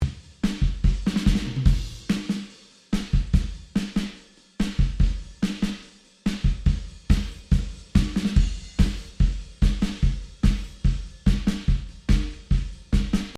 The way it works, in simple terms, is that it samples the drum sound you wish to replace or double, and uses the loudest parts to generate trigger points for Logic’s own built-in samples.
Here are two samples of drums from the upcoming EP to illustrate.
Drums with triggered samples
drums-with-triggered-snare-and-kick.mp3